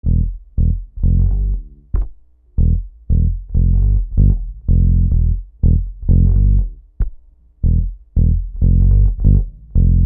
Bass 06.wav